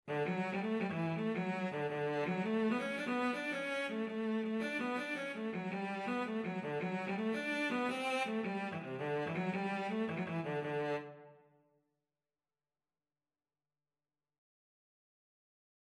6/8 (View more 6/8 Music)
D major (Sounding Pitch) (View more D major Music for Cello )
Cello  (View more Easy Cello Music)
Traditional (View more Traditional Cello Music)